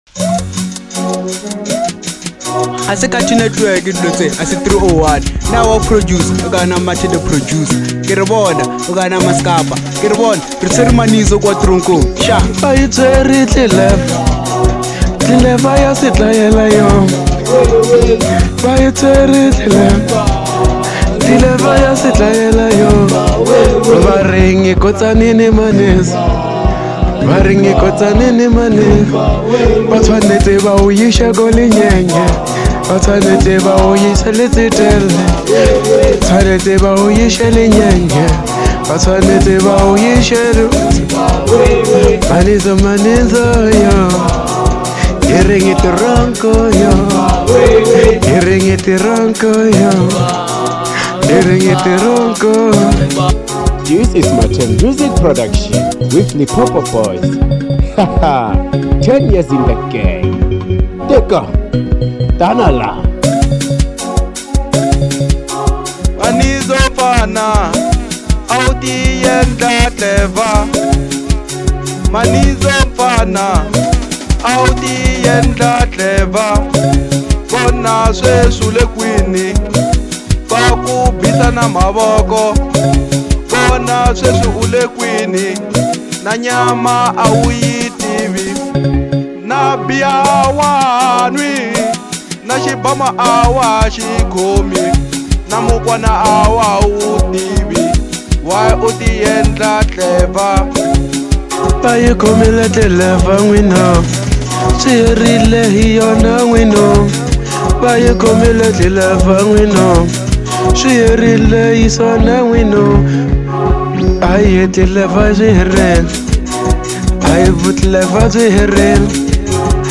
Genre : Manyalo